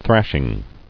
[thrash·ing]